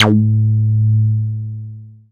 tekTTE63031acid-A.wav